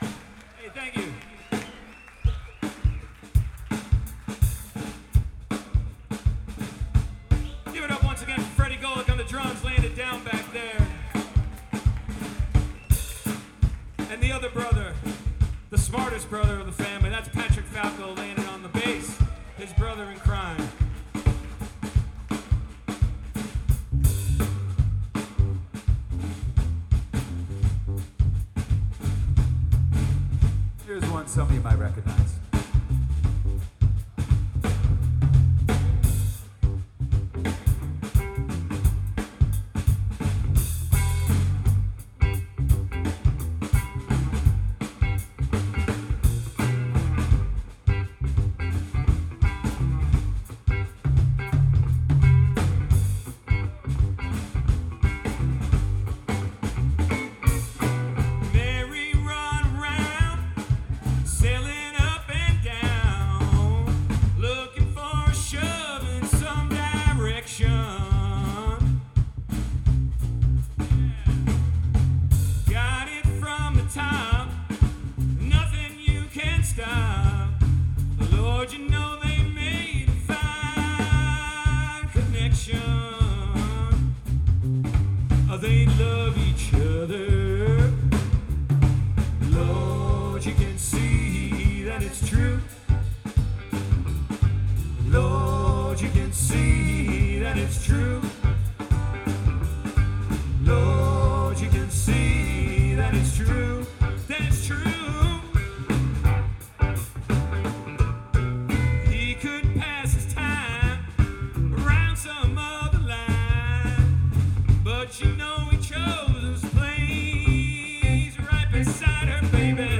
lead vocals, rhythm guitar
harmony vocals, lead guitar
Bass
Drums
Live from The Festy Experience, Nelson Virginia 10/12/14.